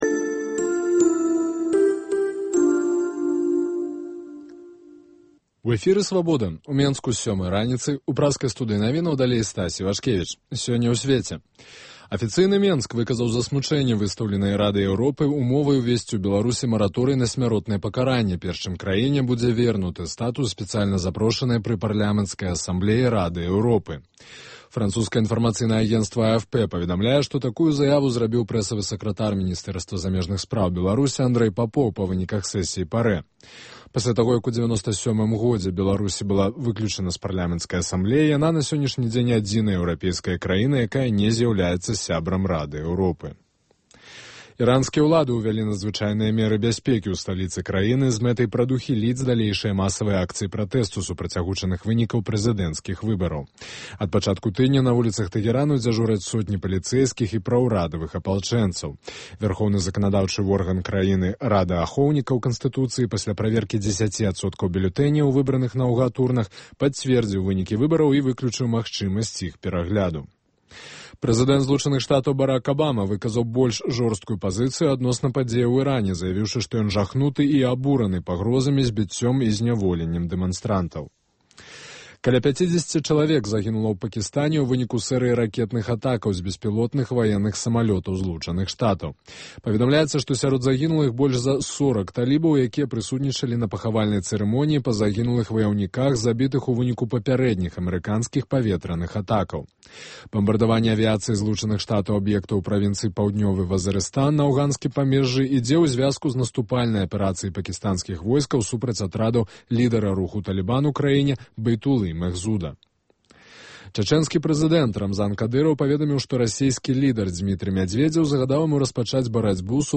Асноўныя падзеі, бліц-аналіз, досьледы і конкурсы, жывыя гутаркі, камэнтары слухачоў, прагноз надвор'я, "Барды Свабоды".